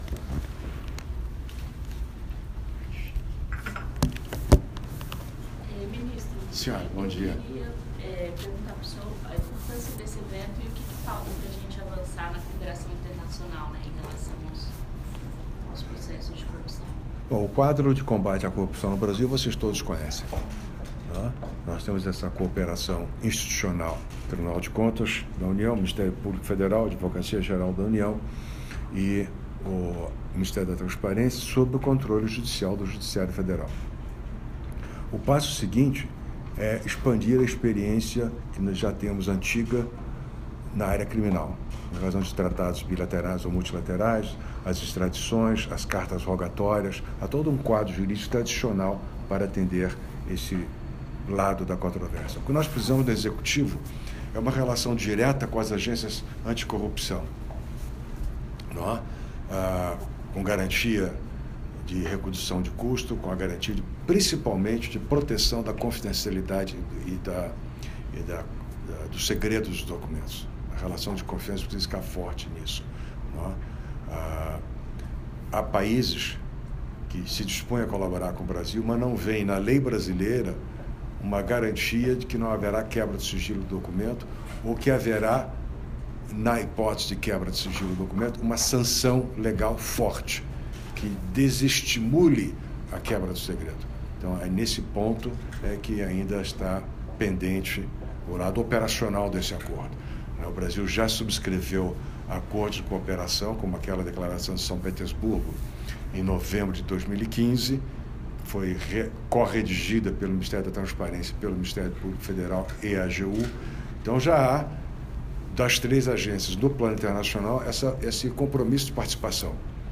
Afirmação foi feita durante abertura de seminário sobre cooperação internacional no âmbito do G20, nesta segunda (10), em Brasília
- Áudio da coletiva do ministro